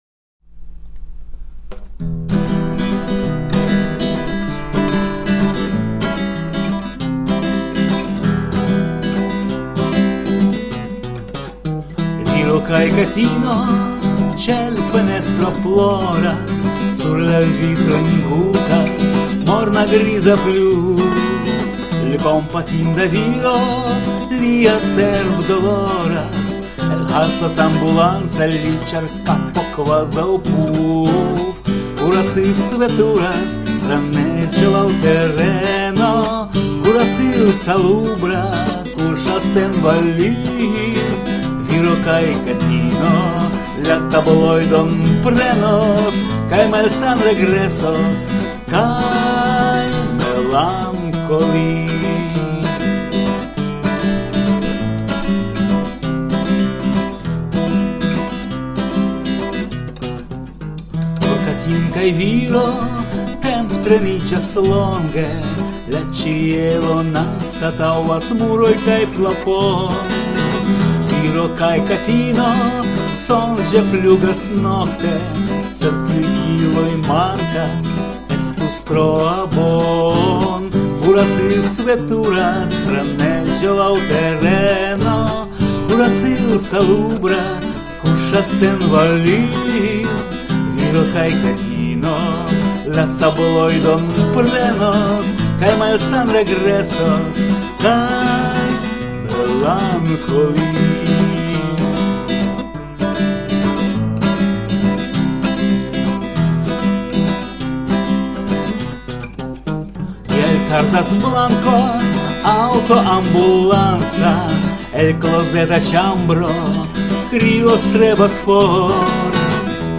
Plenumo esperante kun gitaro